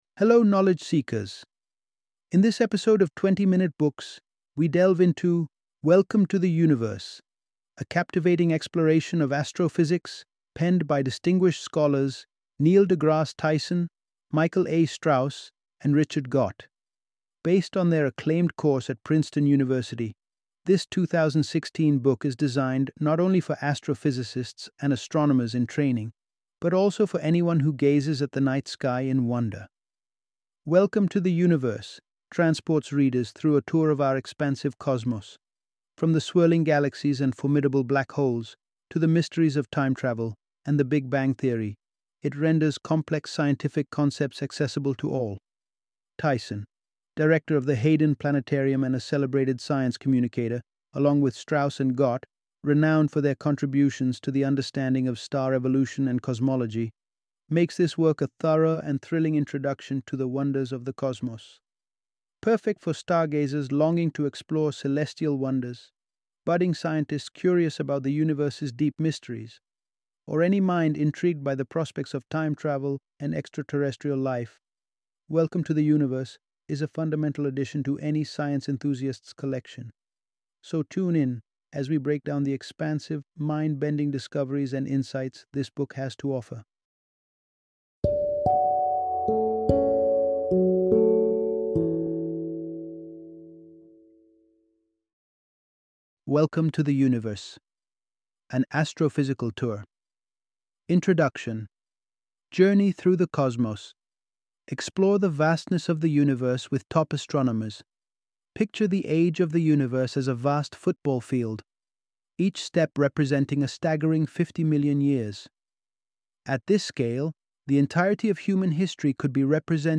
Welcome to the Universe - Audiobook Summary